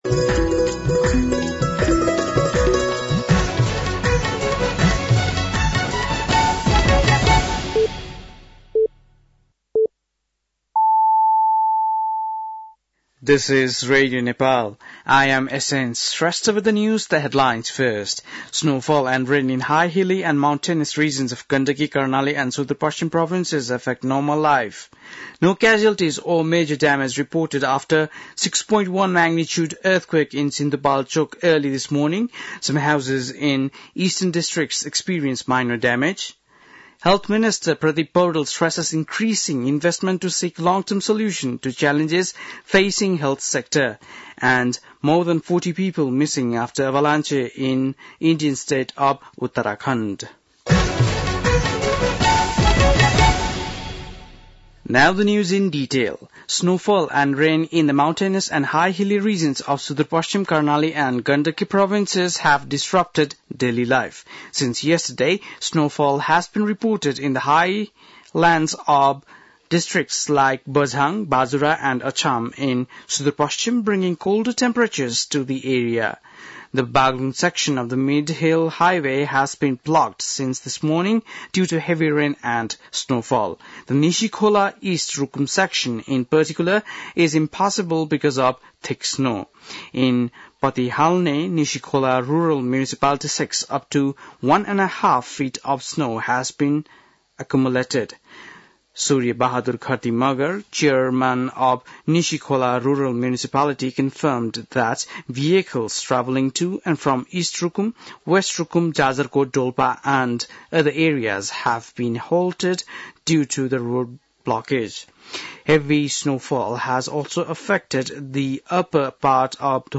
बेलुकी ८ बजेको अङ्ग्रेजी समाचार : १७ फागुन , २०८१
8-PM-English-NEWS-11-16.mp3